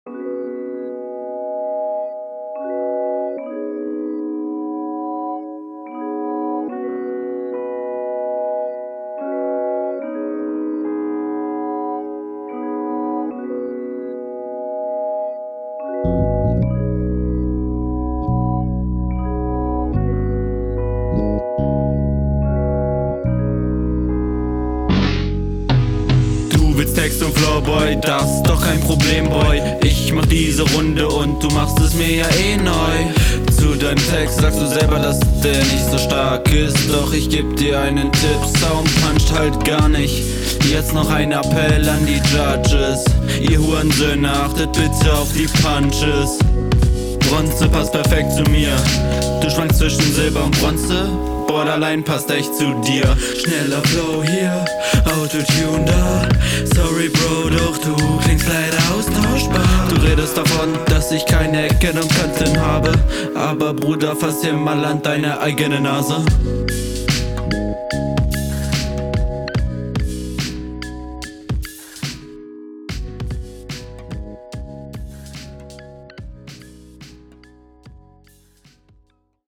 Flow ist ein bisschen holprig aber wird besser.
Der Stimmeinsatz ist ganz solide, manchmal ein wenig monoton, aber ok.